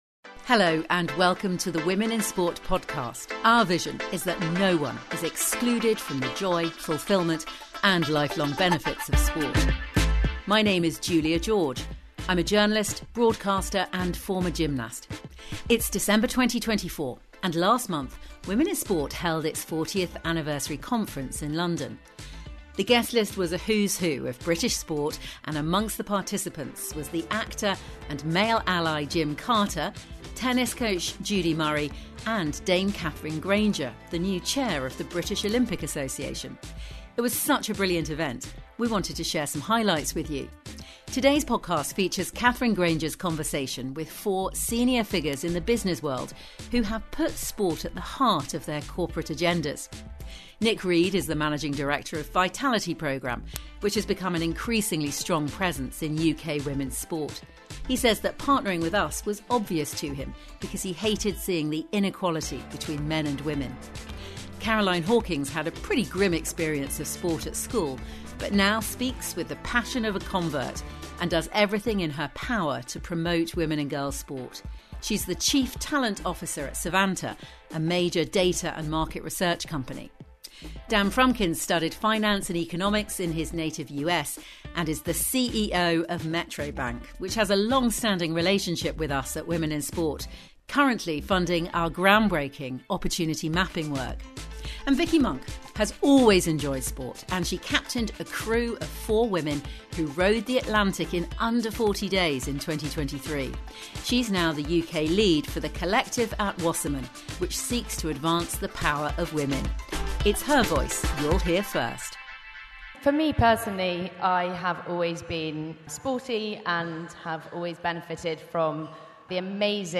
In November 2024 Women in Sport held our 40th anniversary conference in London.